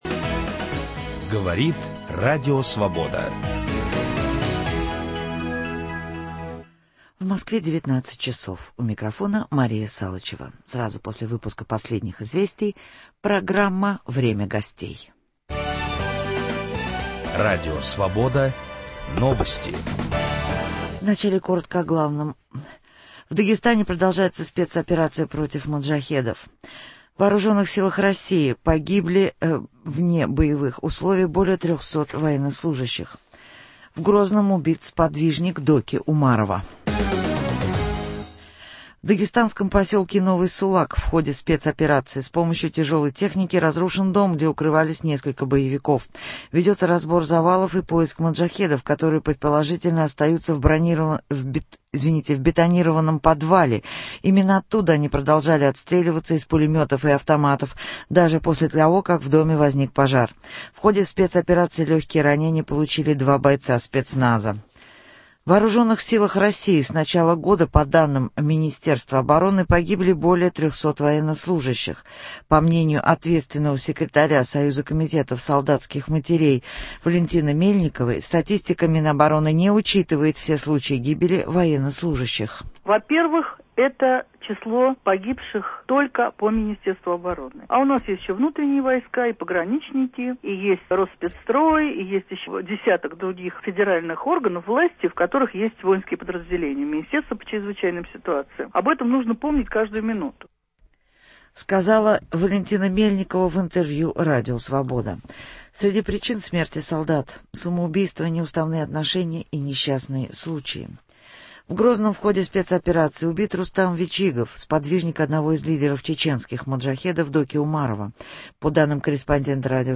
В Московской студии - Александр Лебедев, депутат Государственной Думы России.